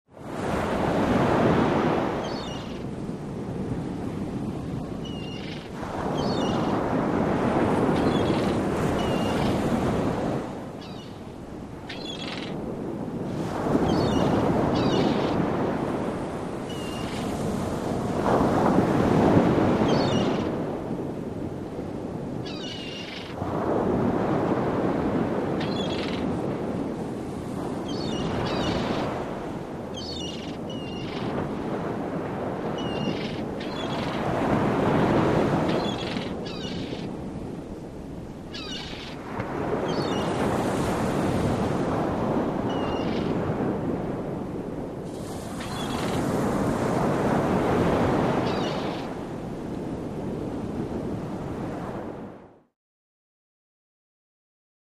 Surf | Sneak On The Lot
Beach Ambience With Seagulls In Background.